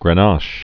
(grən-näsh, grĕn-äsh)